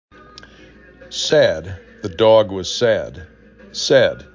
sad 3 /s/ /a/ /d/